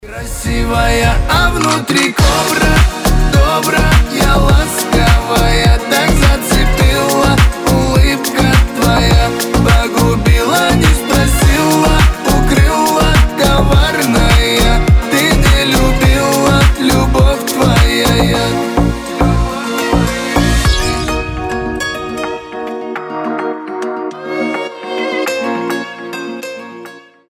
• Качество: 320, Stereo
мужской голос
бьющееся стекло